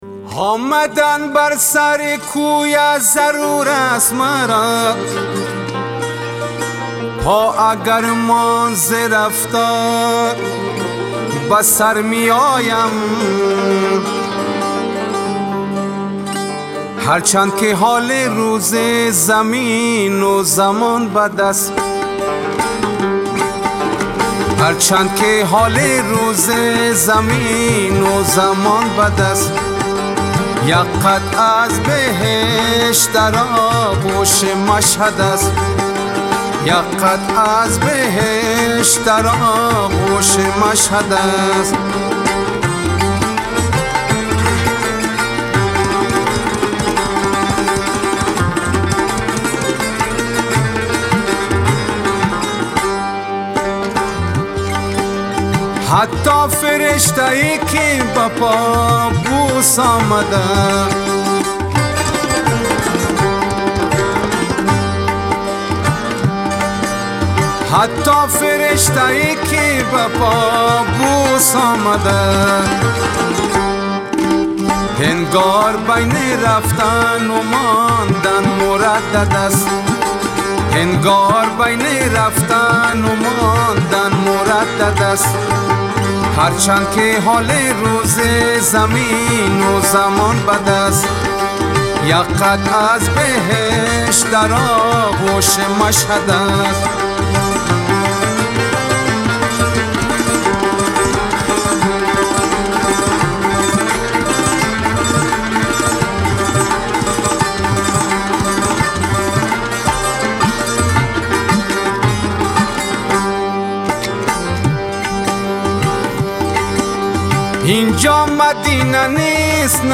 برگرفته از قوالی ها و مناقب خوانی مکتب هرات (افغانستان)